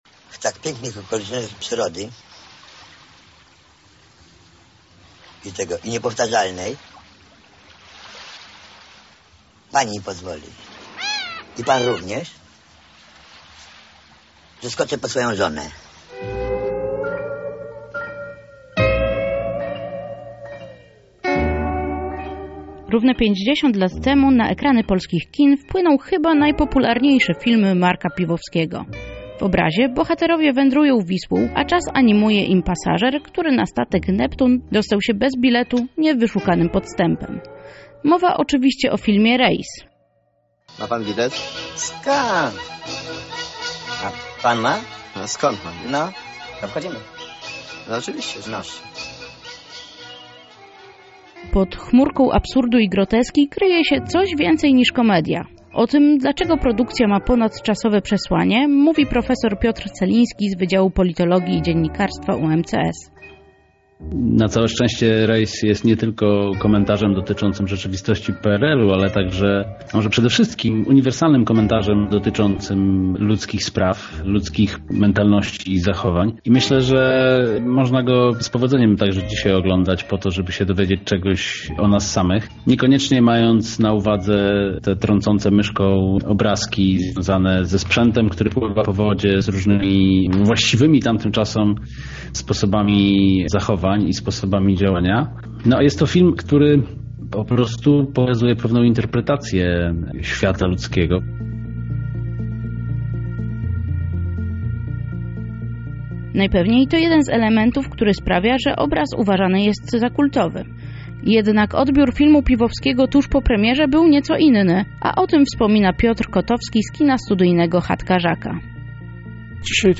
Nasza reporterka spróbowała się dowiedzieć czy film stracił na aktualności i co sprawia, że nosi miano kultowego.